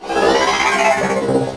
combat / ENEMY / droid / die3.wav